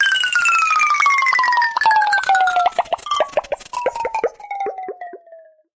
Звуки ксилофона
Звук анимации: сдувание шара